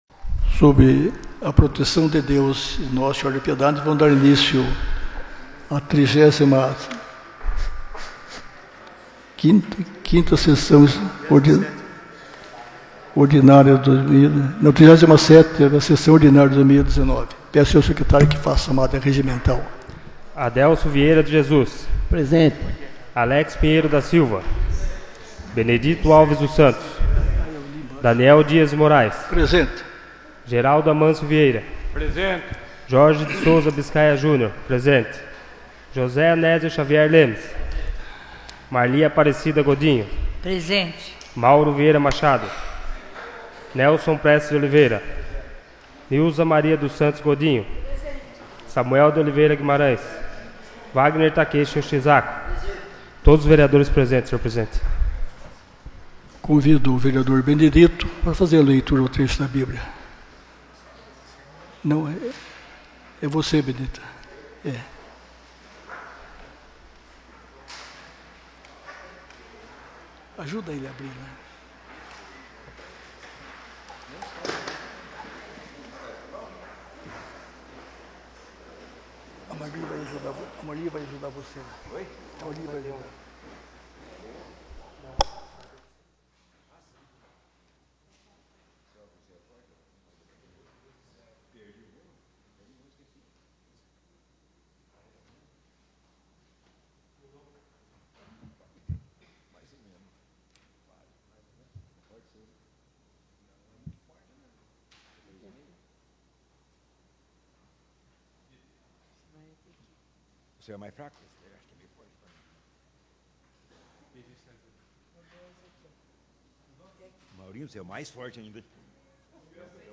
37ª Sessão Ordinária de 2019